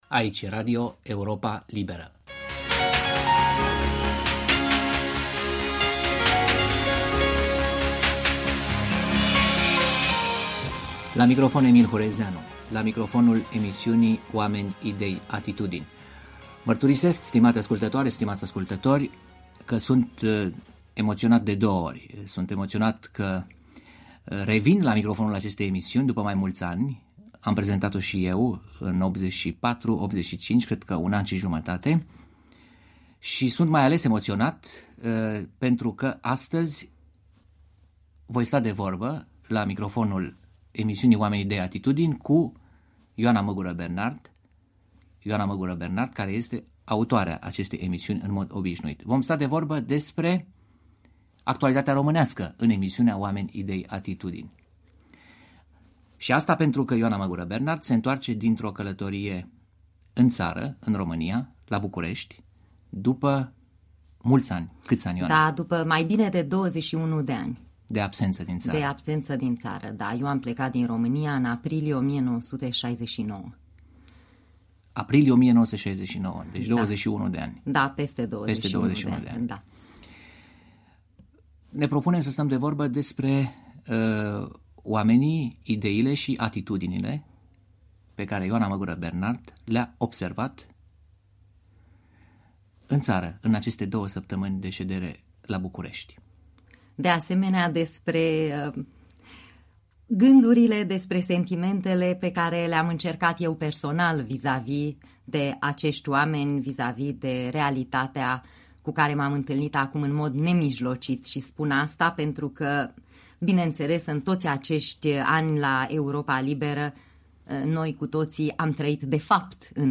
OAMENI, IDEI, ATITUDINI 7 decembrie 1990 Moderator: Emil Hurezeanu Interviu